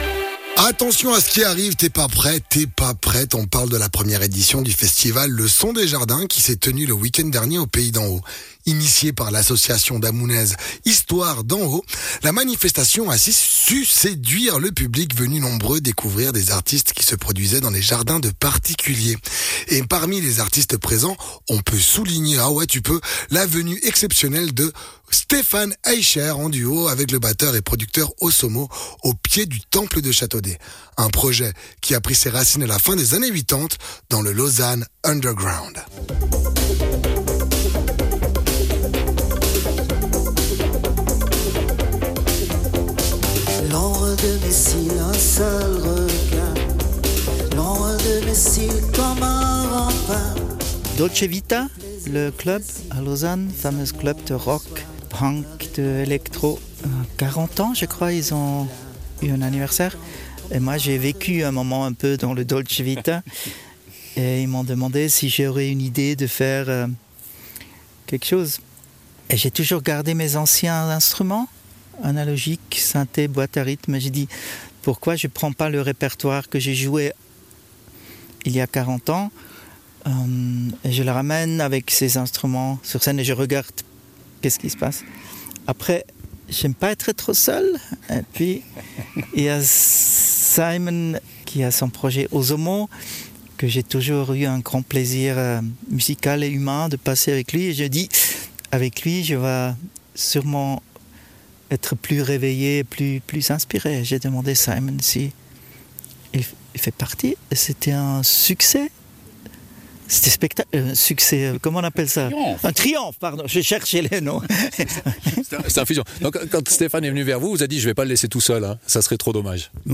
Intervenant(e) : Stephan Eicher, auteur-compositieur-interprète